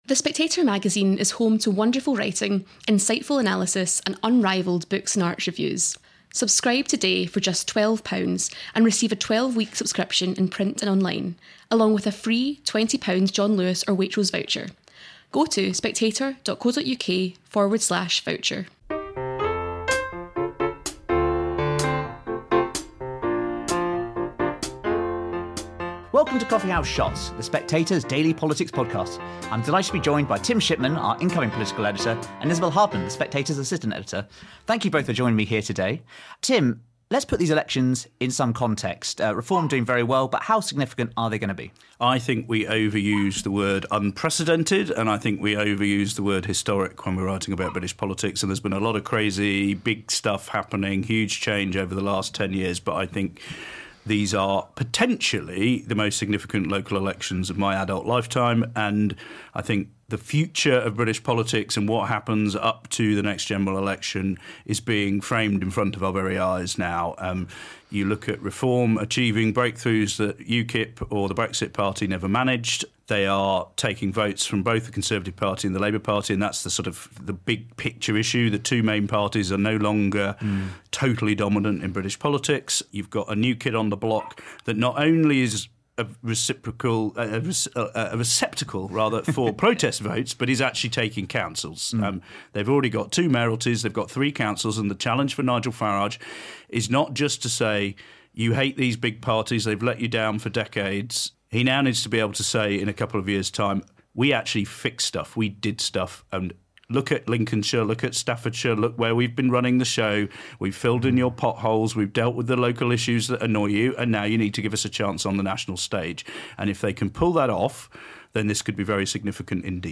This episode was recorded as part of The Spectator ’s local elections live broadcast.